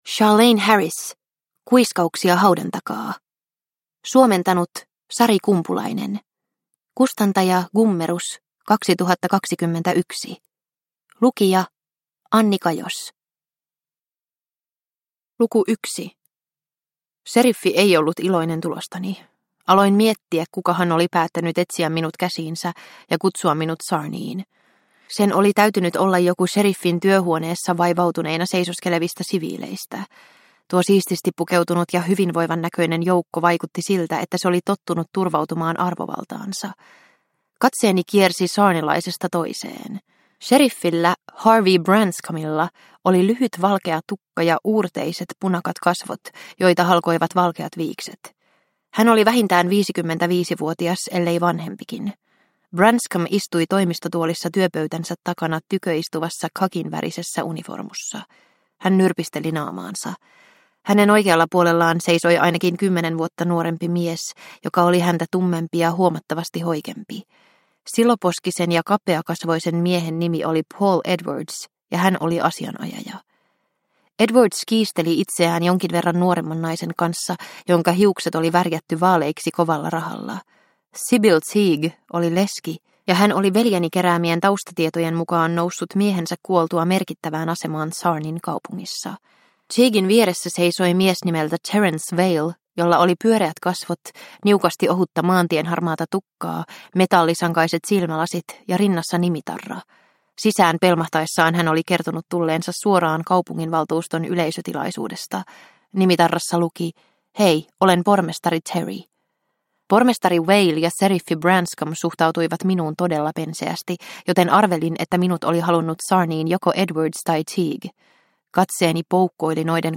Kuiskauksia haudan takaa – Ljudbok – Laddas ner